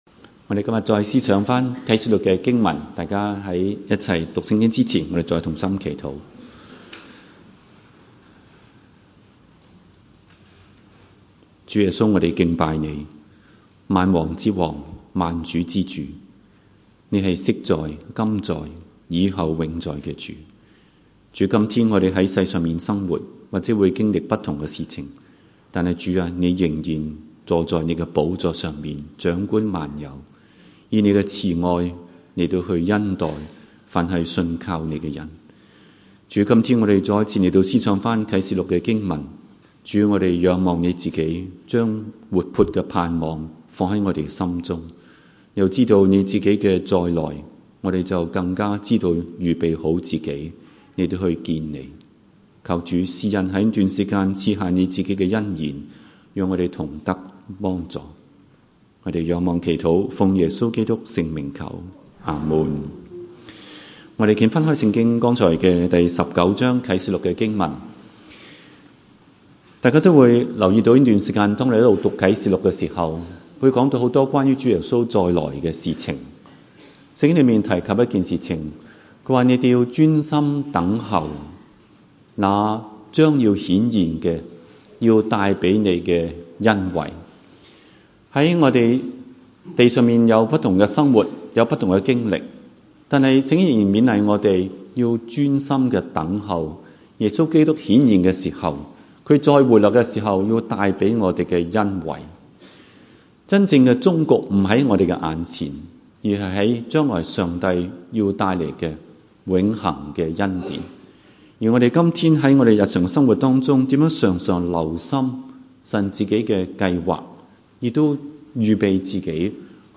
場所：週六崇拜